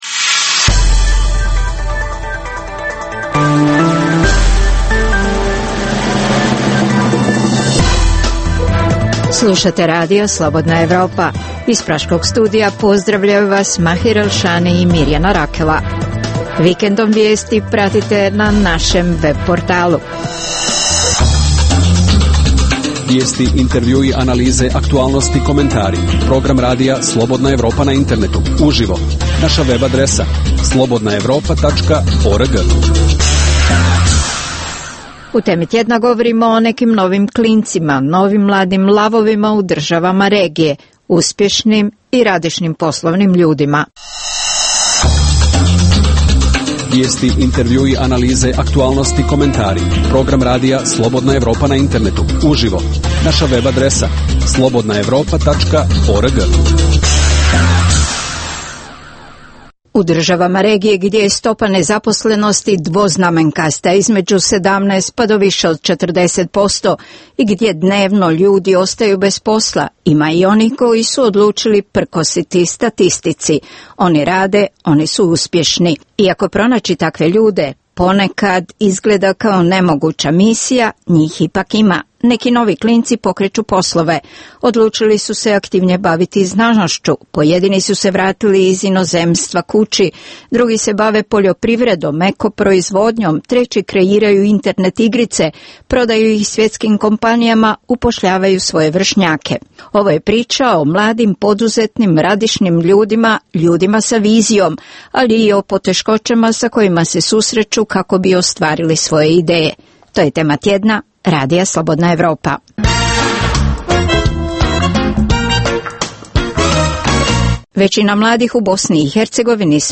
Za Radio Slobodna Evropa govore mladi poduzetnici iz BiH, Srbije, Hrvatske i Crne Gore.